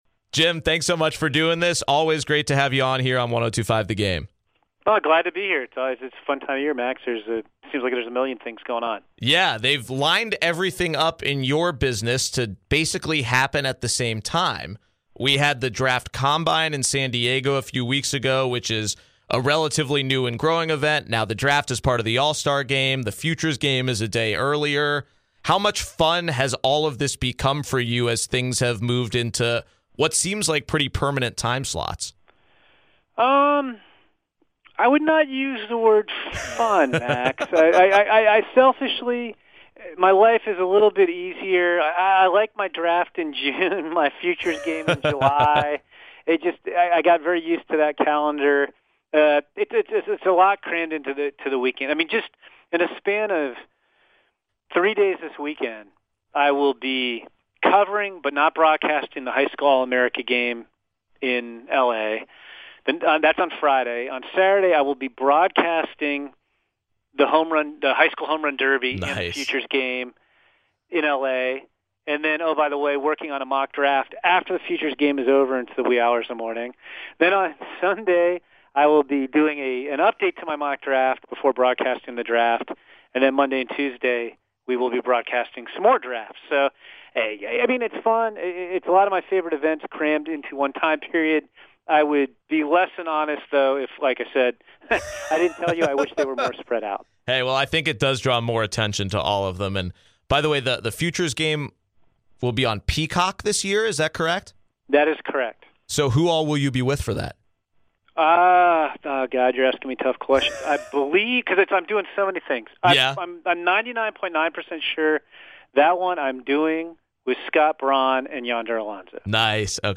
Full Interview, The Six